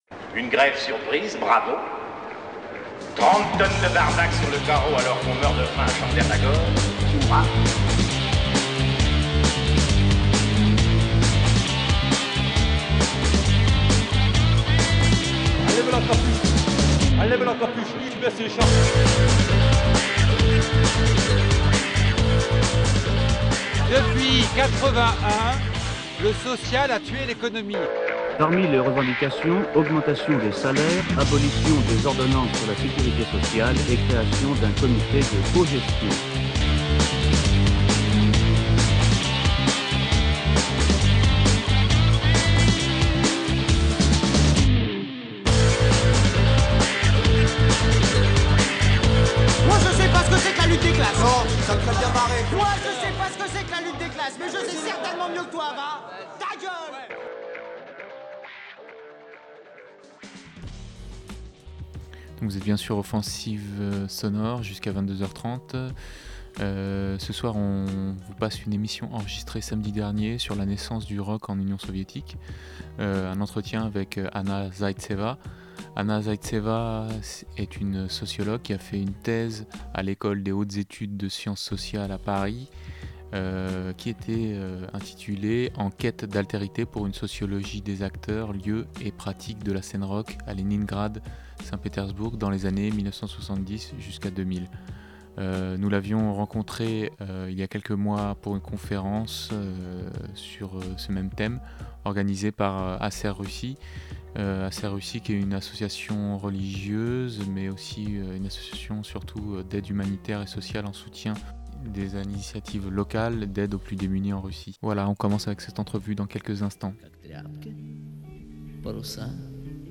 L’émission « Offensive Sonore » est diffusée un vendredi sur deux sur Radio Libertaire de 21h à 22h30 (89,4 Mhz) en alternance avec « Les amis d’Orwel ».